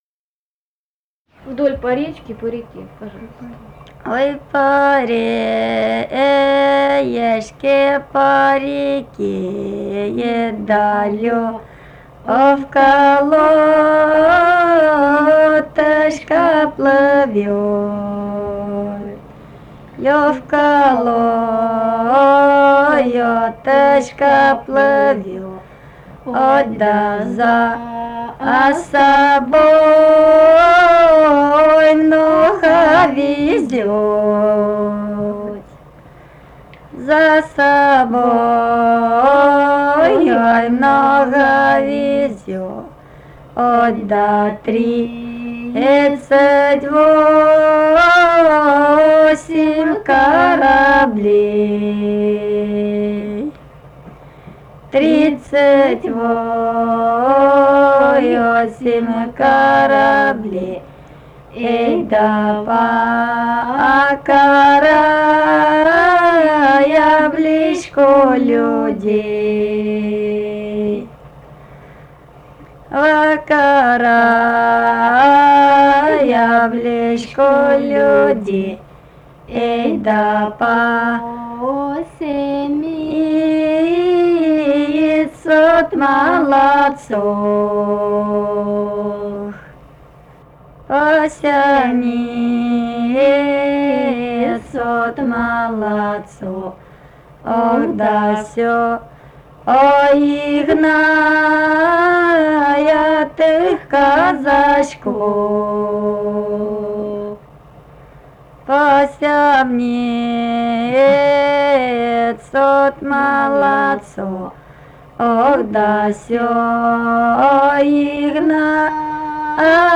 Этномузыкологические исследования и полевые материалы
«Ой, по речке, по реке» (историческая).
Ставропольский край, пос. Новокумский Левокумского района, 1963 г. И0726-07